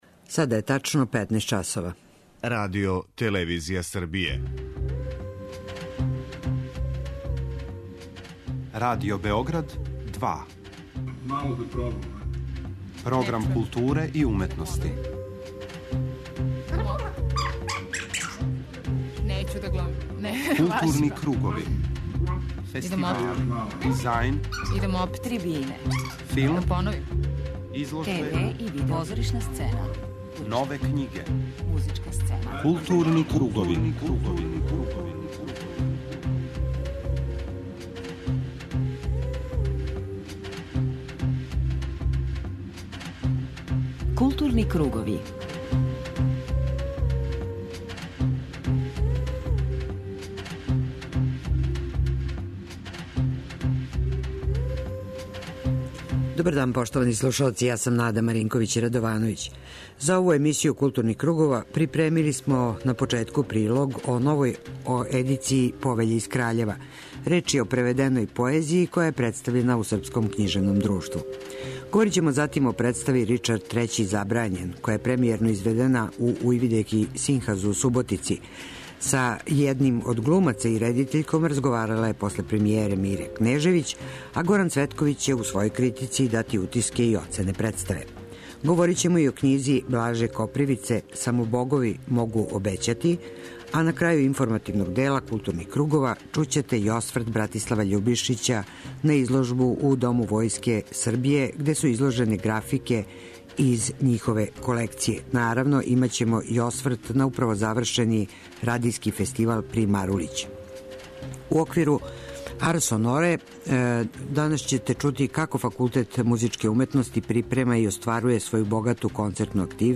преузми : 54.74 MB Културни кругови Autor: Група аутора Централна културно-уметничка емисија Радио Београда 2. Како би што успешније повезивали информативну и аналитичку компоненту говора о култури у јединствену целину и редовно пратили ритам културних збивања, Кругови имају магазински карактер.